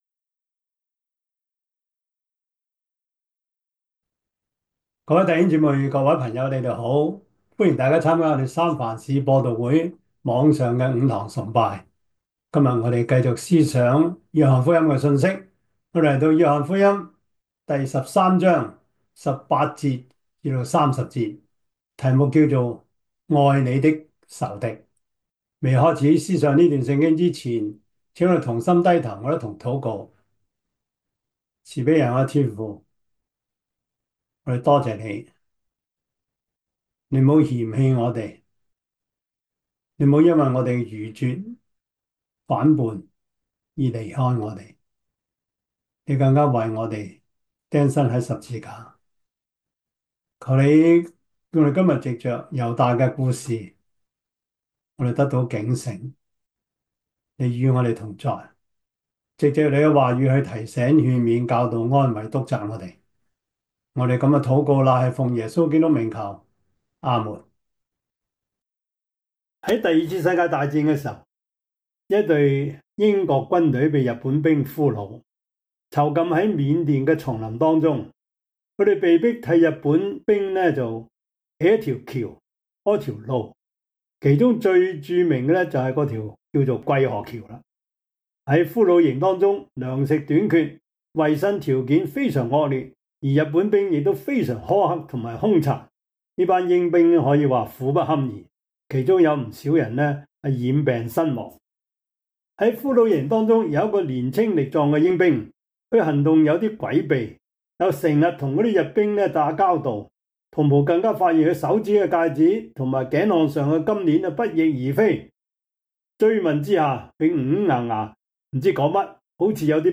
約翰福音 13:18-32 Service Type: 主日崇拜 約翰福音 13:18-32 Chinese Union Version